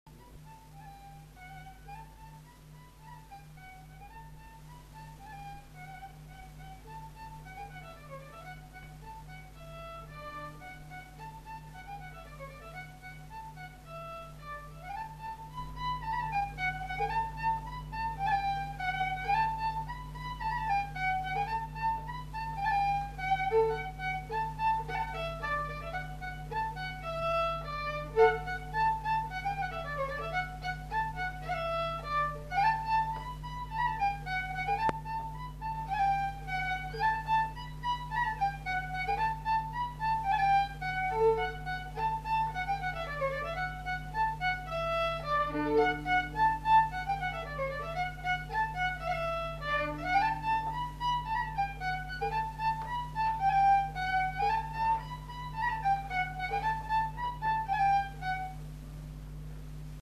Aire culturelle : Gabardan
Genre : morceau instrumental
Instrument de musique : violon
Danse : rondeau